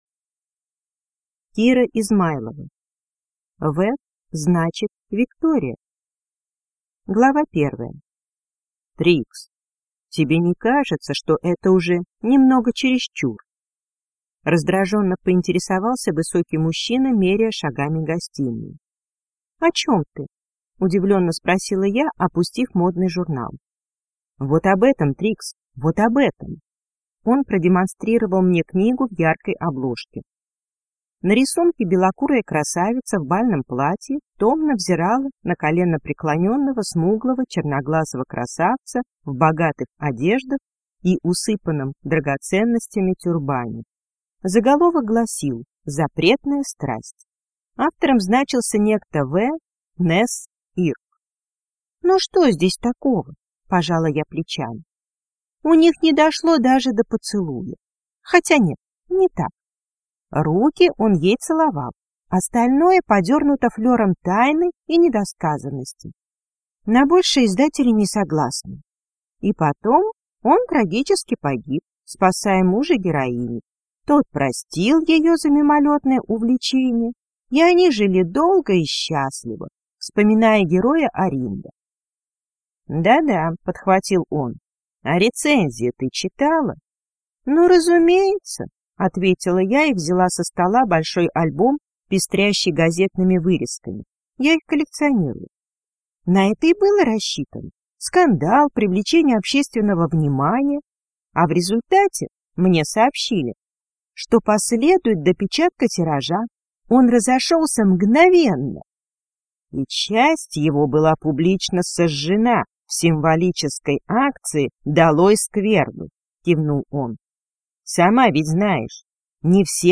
Аудиокнига В – значит виктория | Библиотека аудиокниг